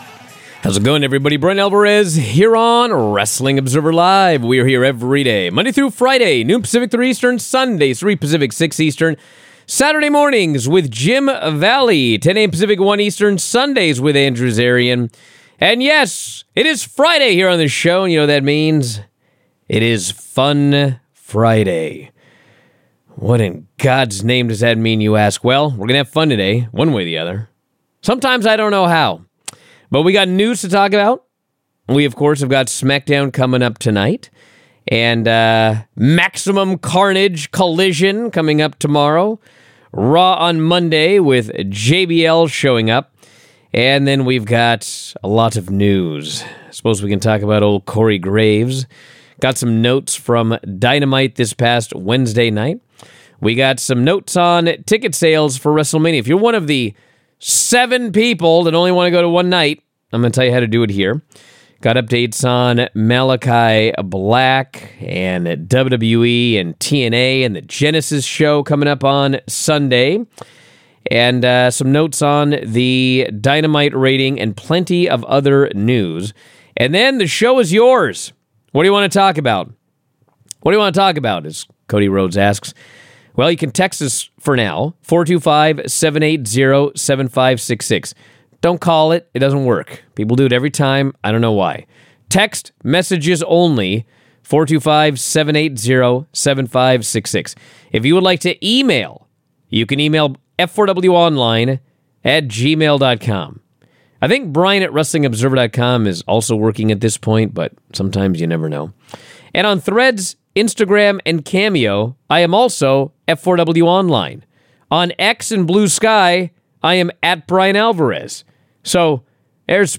take your phone calls and text messages with a bunch of great questions!